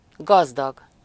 Ääntäminen
IPA: /ˈɡɒz.dɒɡ/